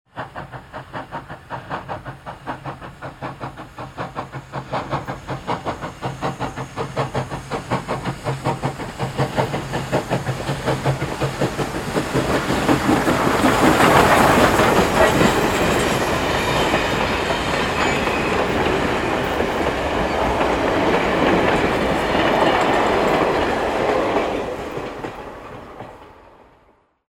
Dampflok-mit-Waggons-schnauft-heran-und-rollt-vorüber.mp3